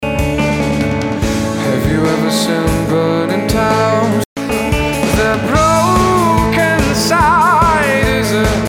Hello guys, could you tell me can I expect better sound quality using Shure PG42 USB for vocals, than this link below?